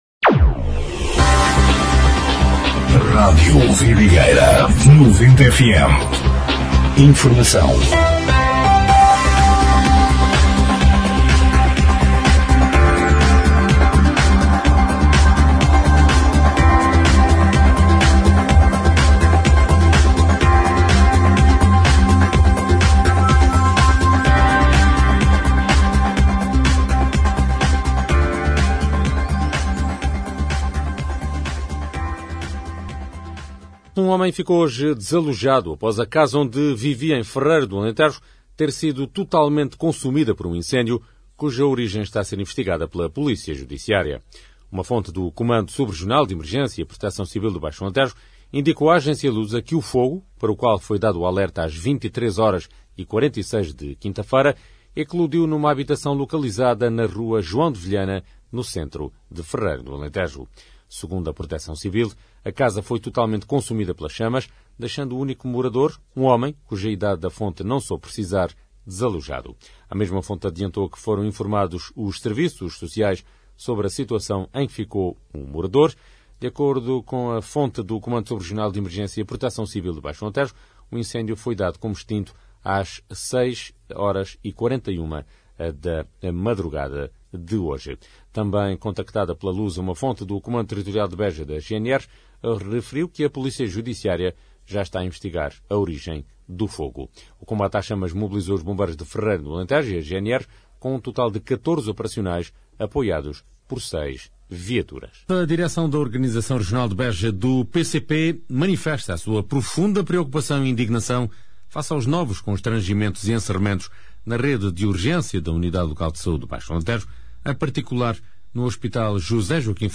Noticiário 09/01/2026